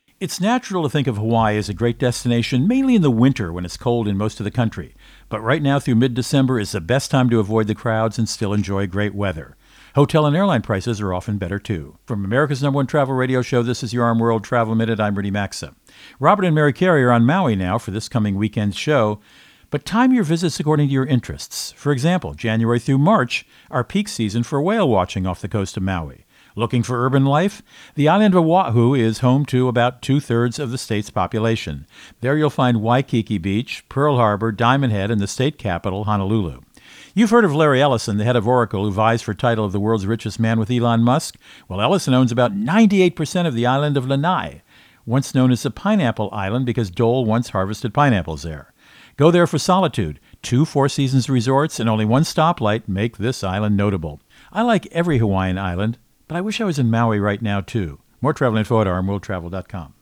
America's #1 Travel Radio Show
Co-Host Rudy Maxa | Travel to Hawaii Tips